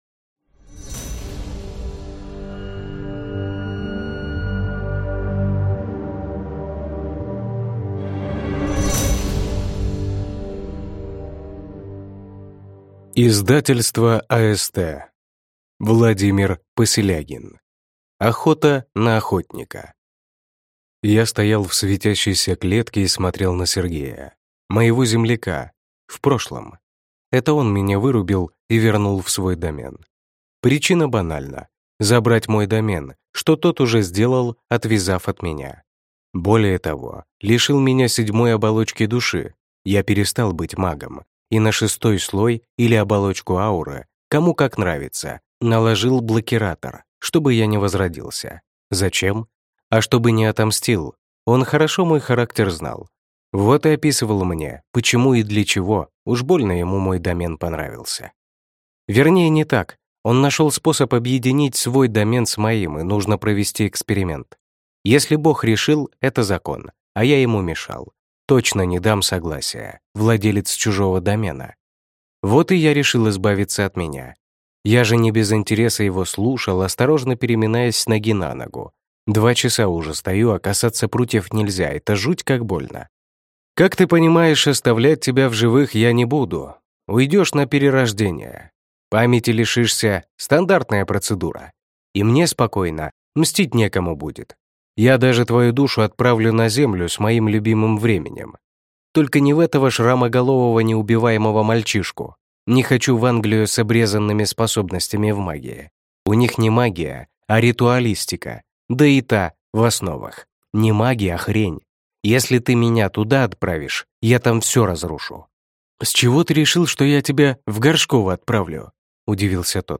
Аудиокнига Охота на охотника | Библиотека аудиокниг